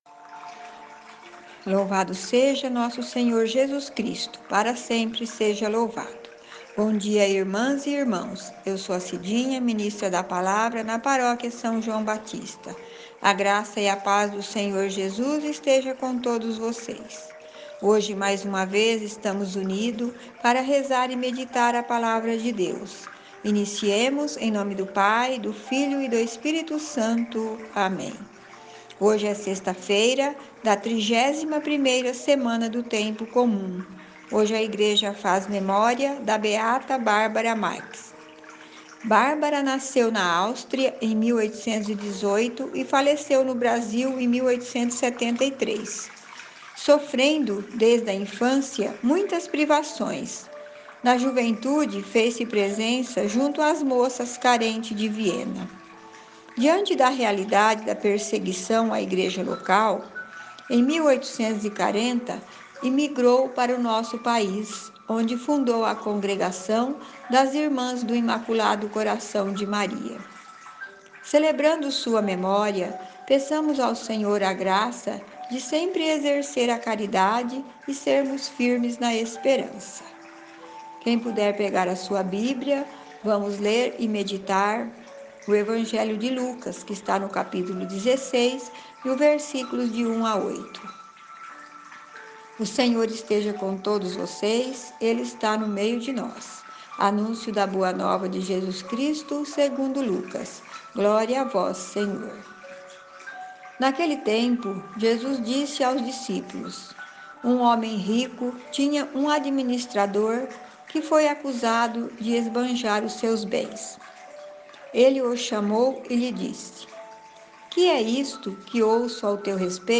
Evangelho do Dia